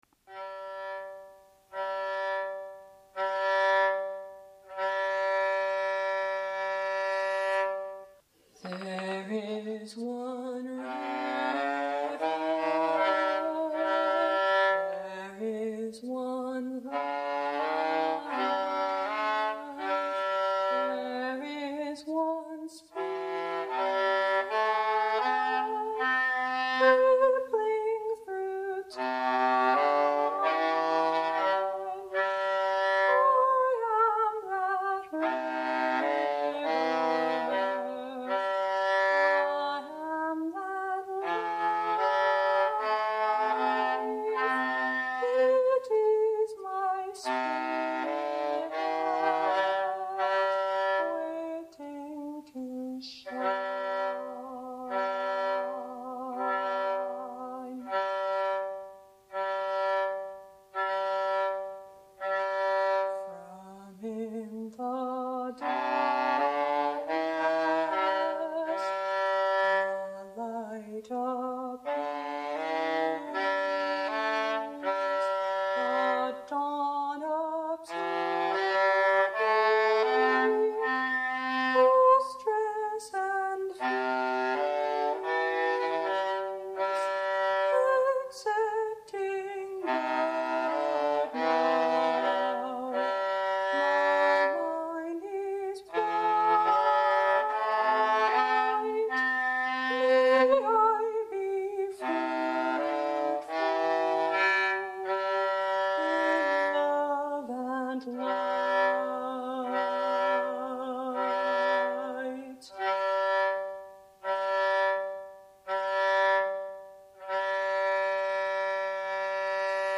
A song for meditational prayer treatment.
Instrument: Concert Viola